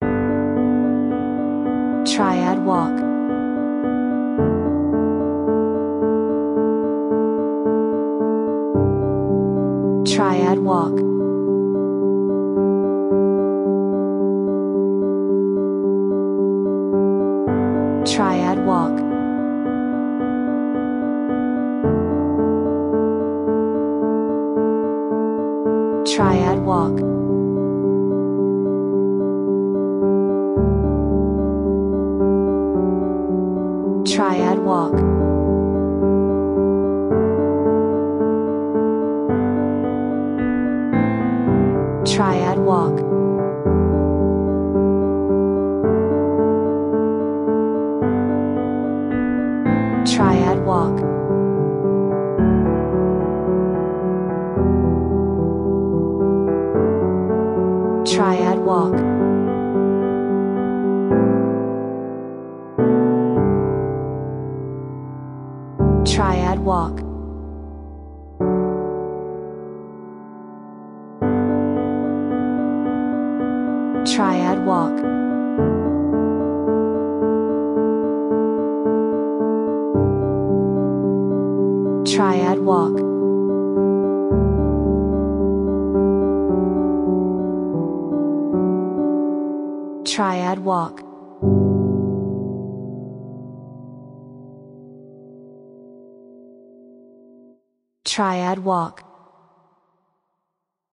【Free Download】 beautiful piano music